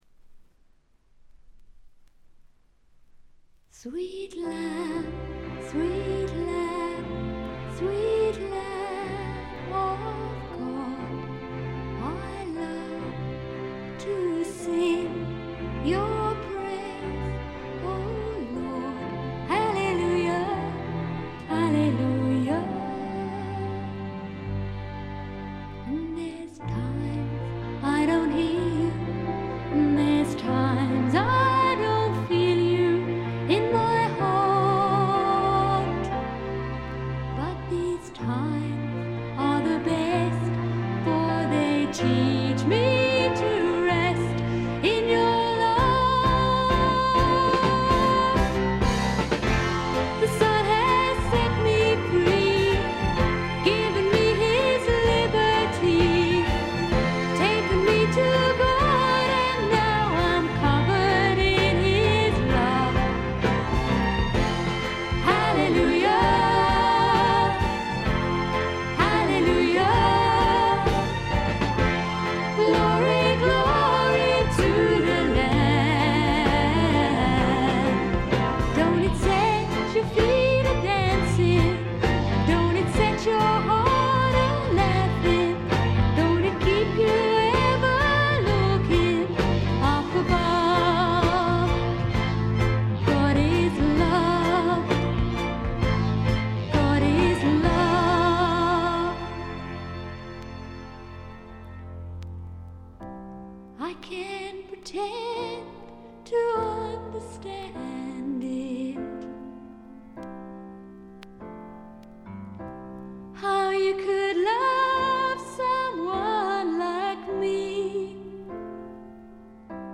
部分試聴ですが静音部での軽微なチリプチ程度。
古くからクリスチャン・ミュージック系英国フィメールフォークの名盤として有名な作品ですね。
試聴曲は現品からの取り込み音源です。